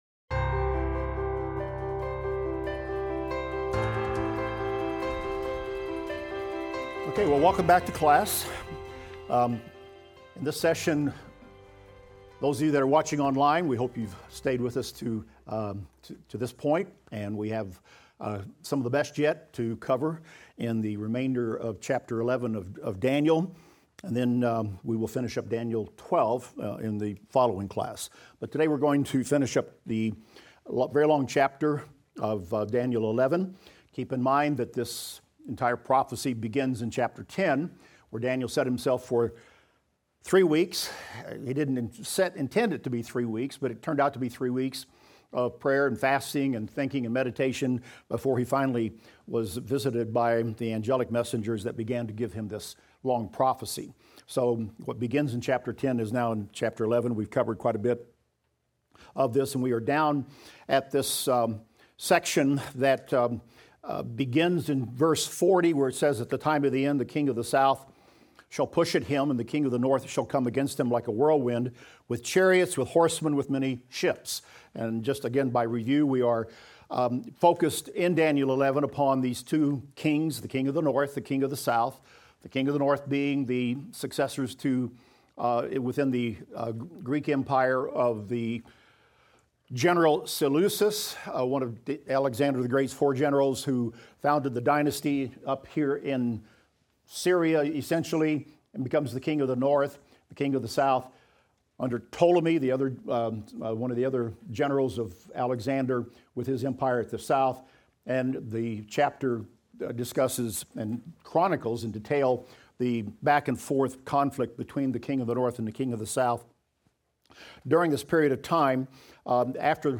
Daniel - Lecture 22 - audio.mp3